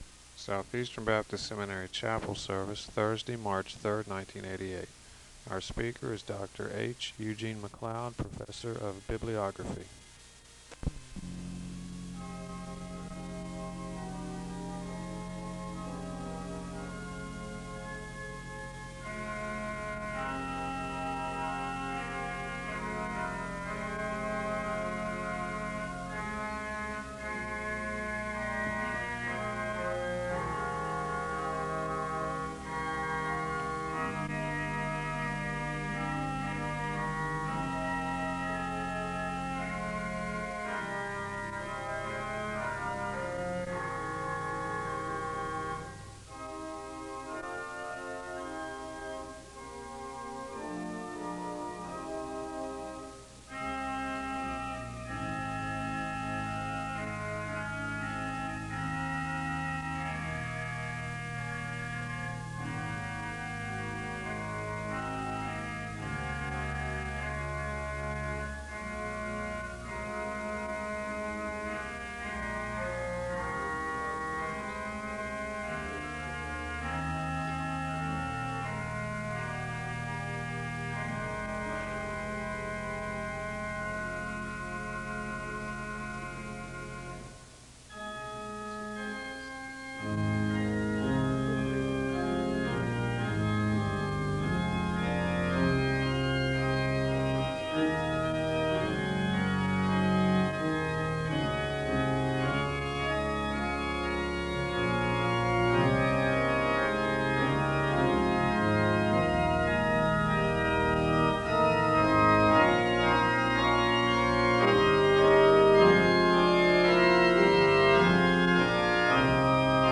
The service begins with organ music (0:00-3:00). There is a moment of prayer (3:01-5:12).
The service concludes with a blessing (20:01-20:37).
SEBTS Chapel and Special Event Recordings - 1980s